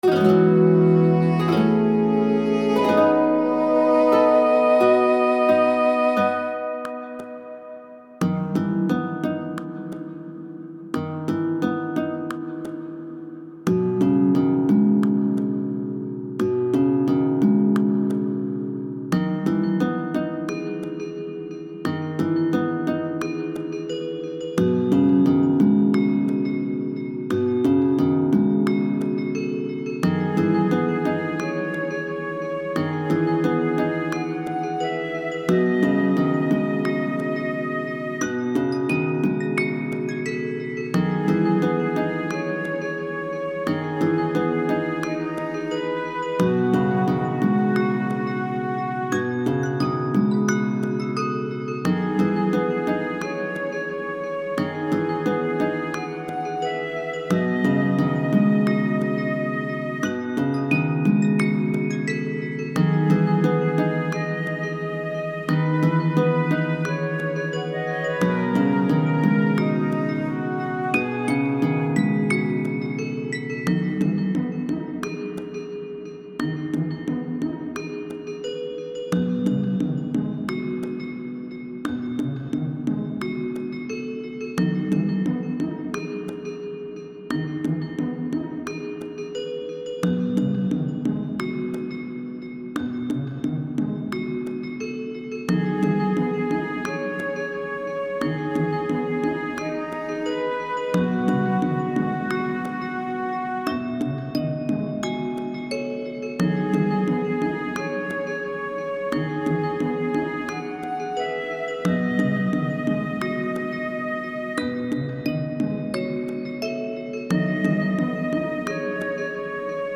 really emotional and chill